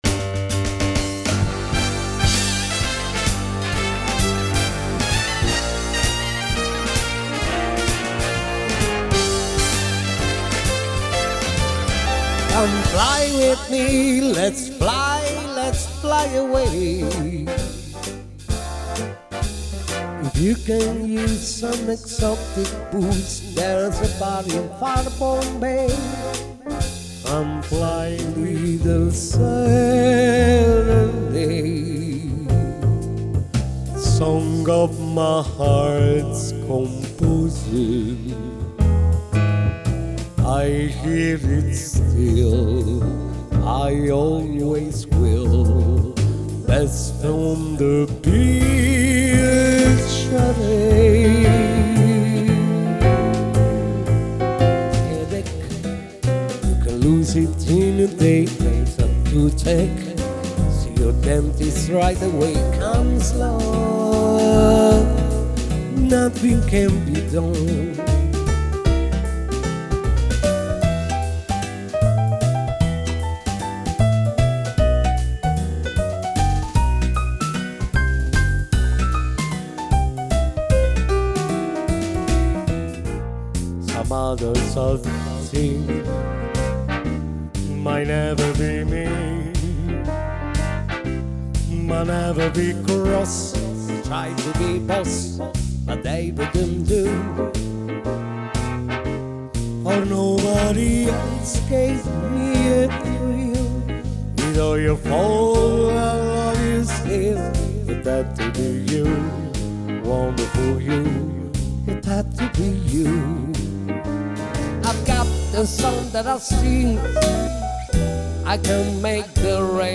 Piano Power
Swing time
MedleySwingFullInstruments.mp3